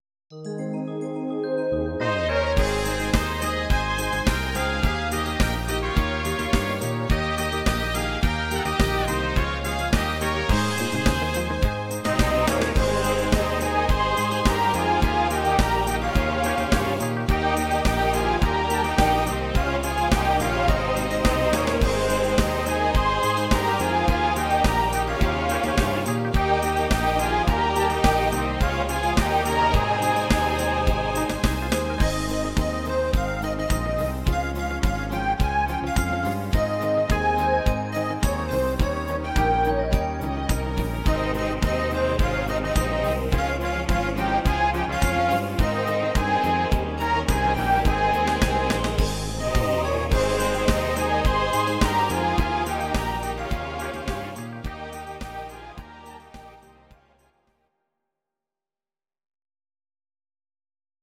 Audio Recordings based on Midi-files
German, Traditional/Folk, Volkstï¿½mlich